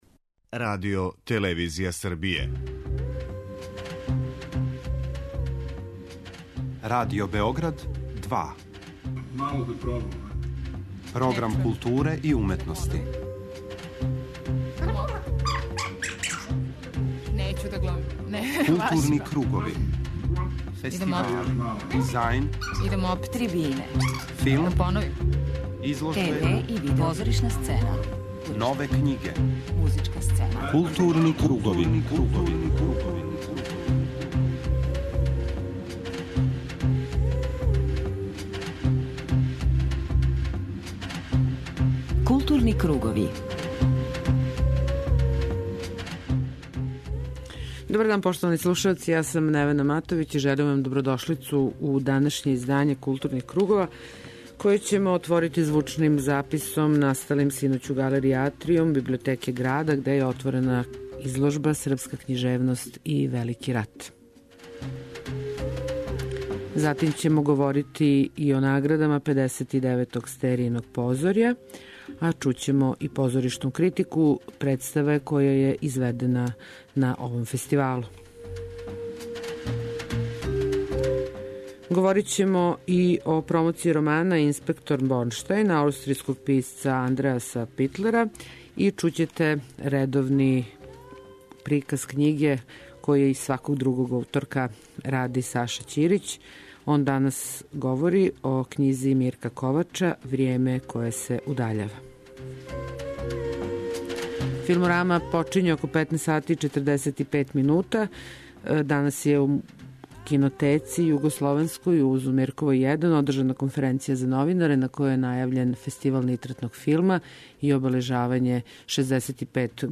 Тим поводом је у Србији боравио директор Мосфилма, редитељ Карен Шахназаров, који је у Филмском центру Србије представио своје предузеће, као и модел финансирања кинематографије у Русији. У данашњем темату ћете чути најзанимљивије делове тог излагања.